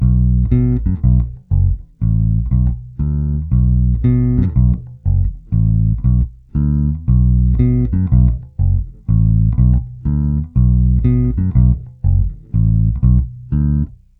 Zvuk: Fender American Deluxe V, oba snímače, korekce rovně, předzesilovač Fender TBP 1, korekce rovně, nahráno linkou (omluvte cvrčka).
Struna H
"Slap"